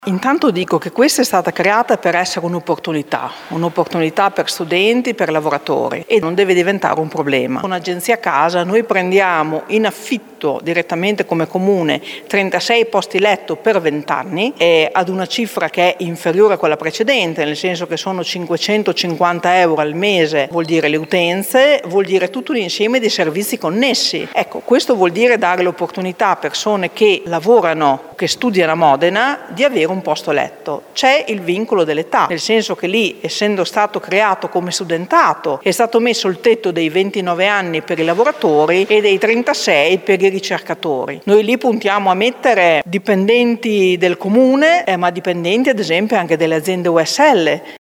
La risposta dell’assessore alle politiche sociali Francesca Maletti: